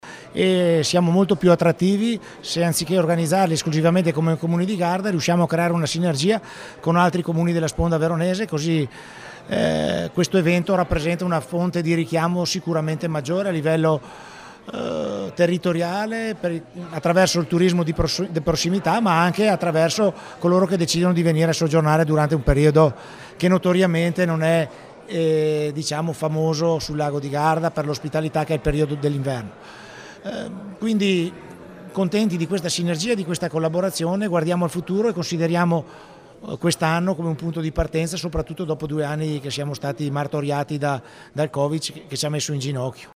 Al nostro microfono Davide Bendinelli, sindaco di Garda